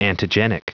Prononciation du mot antigenic en anglais (fichier audio)
antigenic.wav